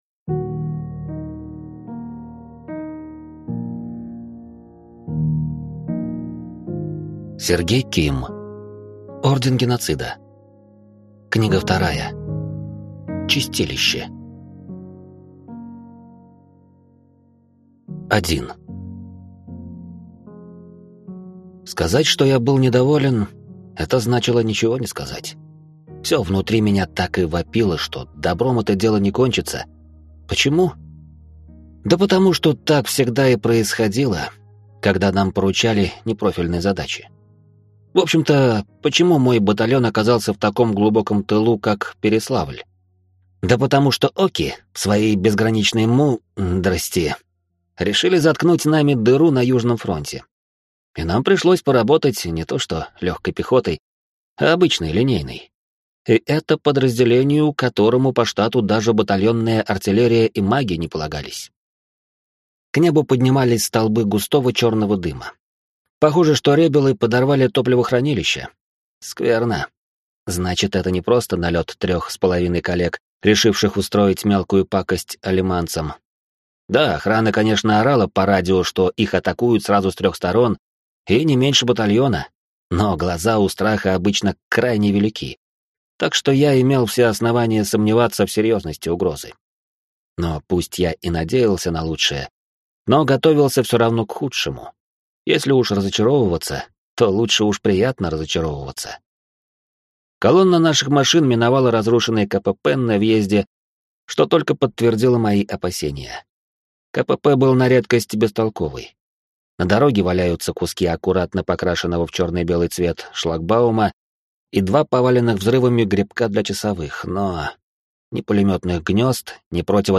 Аудиокнига Чистилище | Библиотека аудиокниг